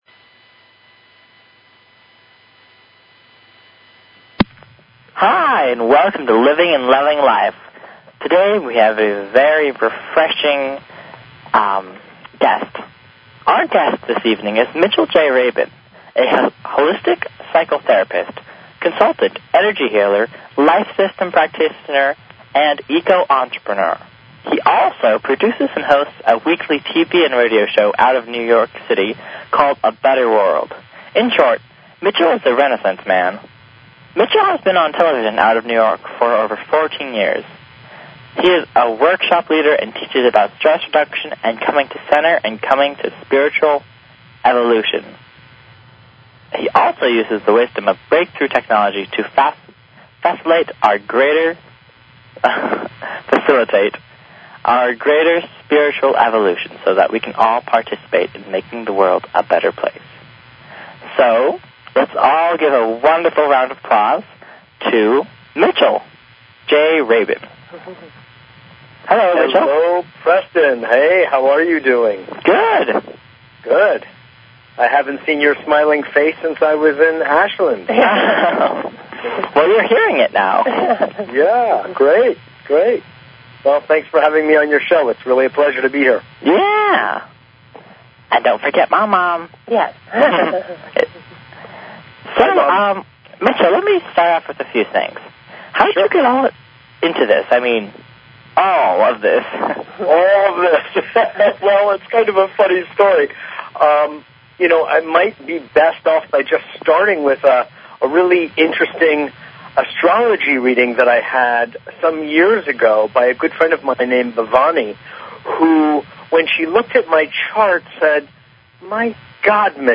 Talk Show Episode, Audio Podcast, Living_and_Loving_Life and Courtesy of BBS Radio on , show guests , about , categorized as